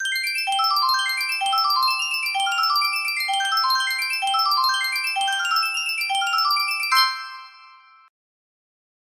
Sankyo Miniature Music Box - How Dry I Am FR music box melody
Full range 60